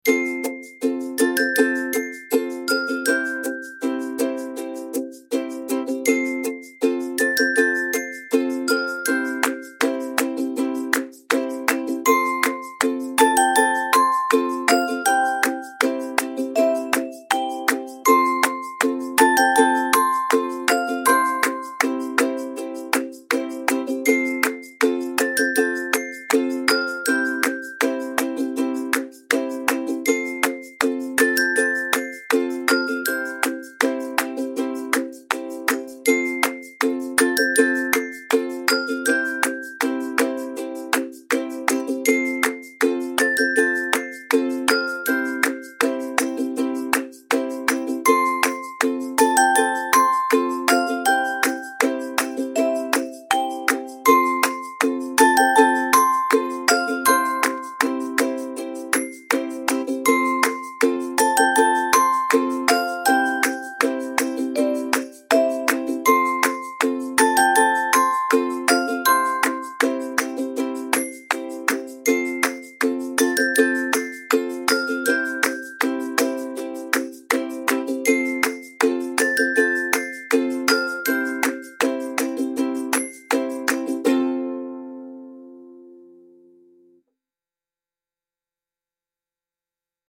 cheerful playful children music with xylophone, ukulele and clapping